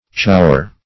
chaussure - definition of chaussure - synonyms, pronunciation, spelling from Free Dictionary Search Result for " chaussure" : The Collaborative International Dictionary of English v.0.48: Chaussure \Chaus`sure"\, n. [F.] A foot covering of any kind.